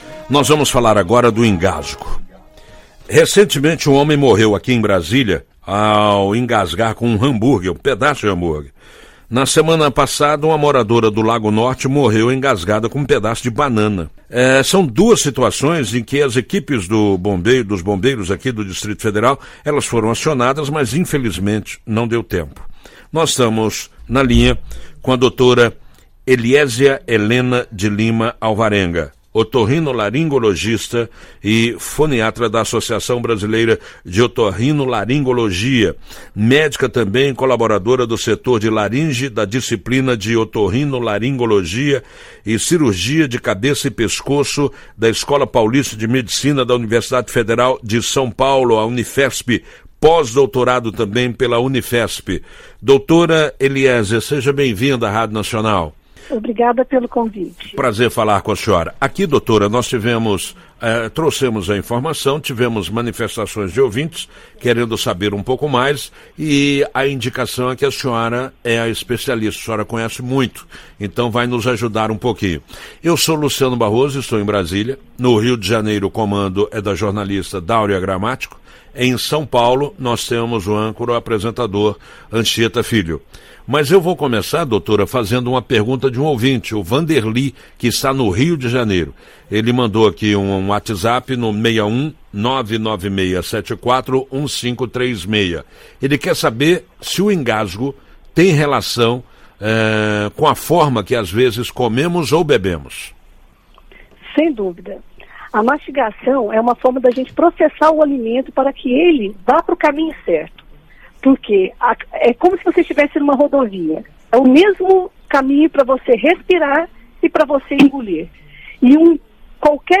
entrevista-ebc-set2018.mp3